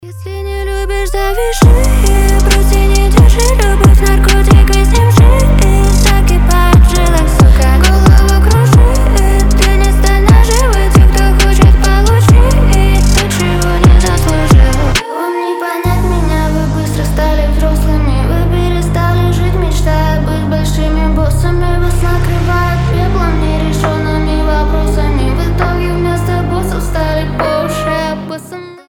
атмосферные
женский голос